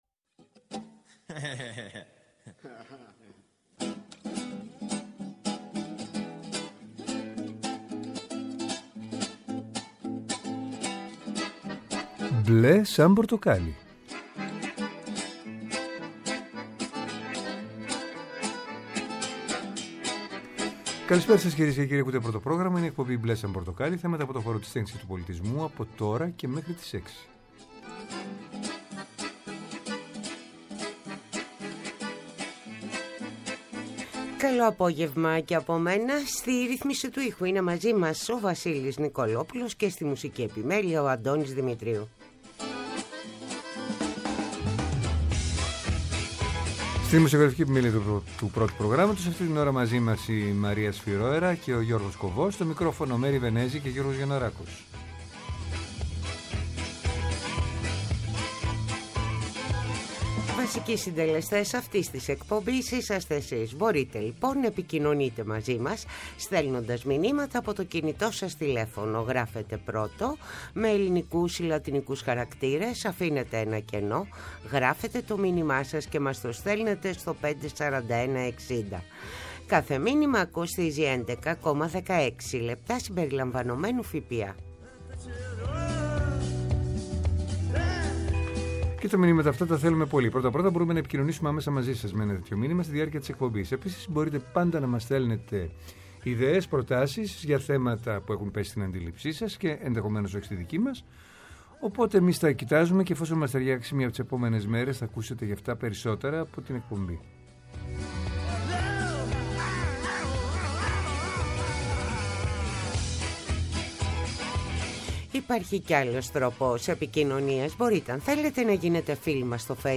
Τηλεφωνικά καλεσμένοι μας είναι οι: